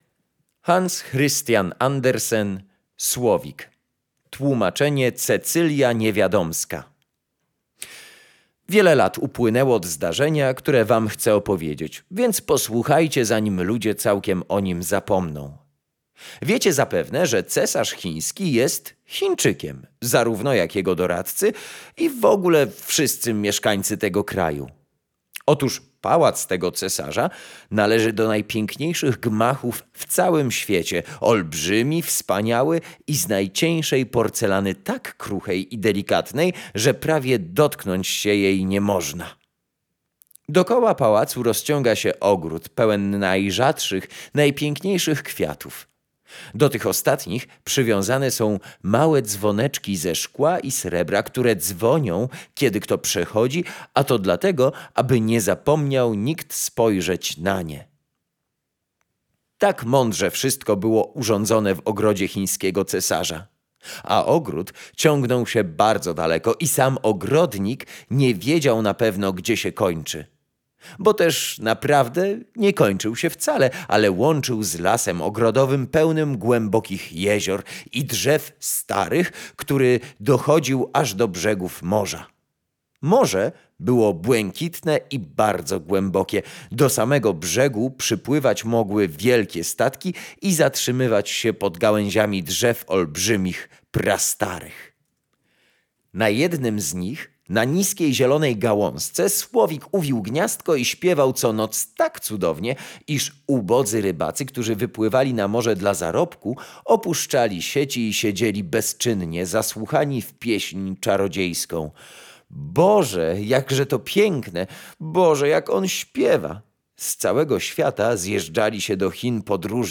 Bajki, legendy, mity, opowieści - lubię czytać i chętnie poczytam zostawiając przy okazji coś dla innych.